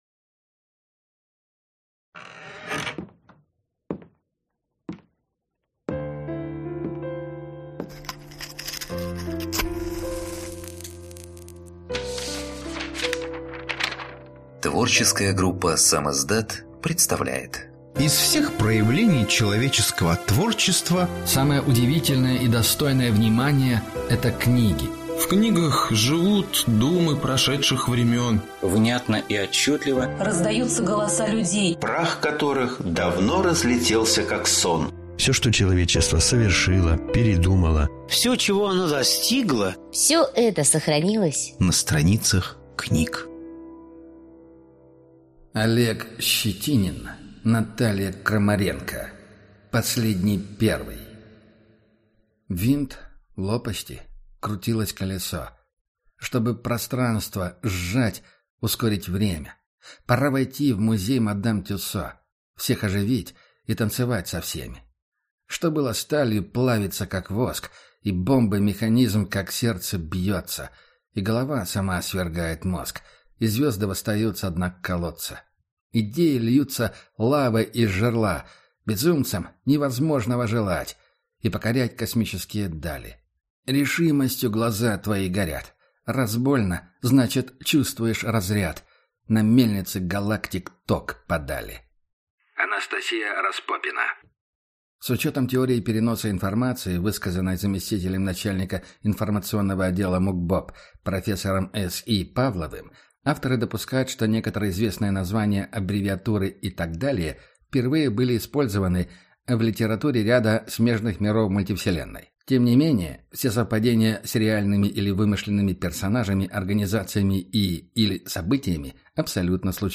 Аудиокнига Последний Первый | Библиотека аудиокниг